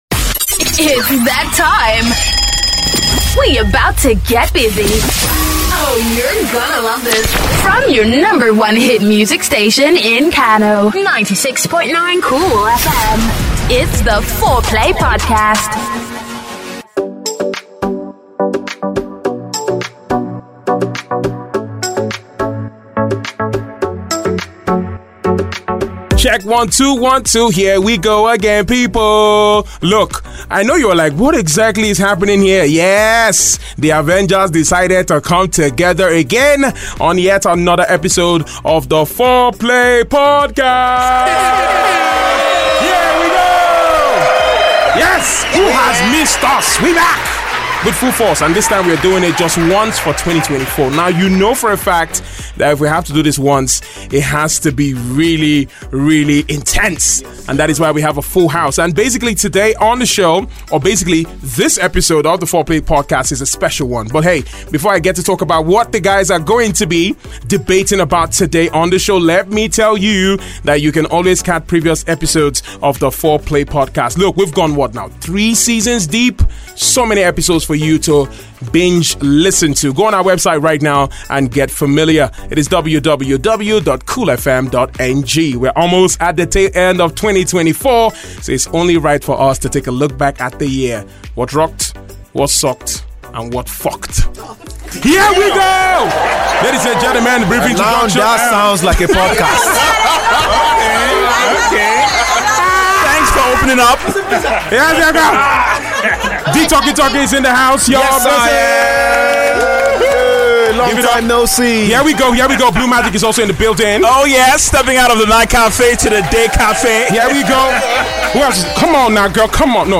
‘Fourplay’ is a biweekly (every two weeks) podcast about everything from trending events to pop culture. It is raw, laidback, and funny making it a great background listening for tedious tasks. It feels like eavesdropping on a convo between pals because that's exactly what it is.